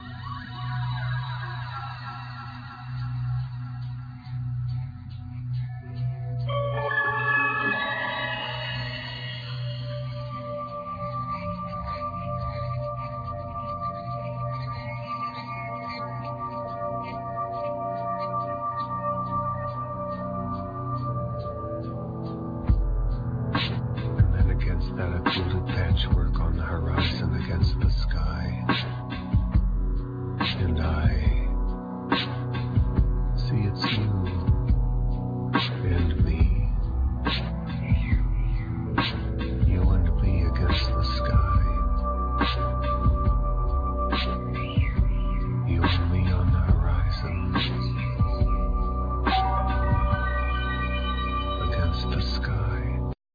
Acoutic & electric piano,Vocals
Keyboards,Electronics,Loops,Electric piano
Pedal steel guitar
Trumpet
Treated Kantele
Double bass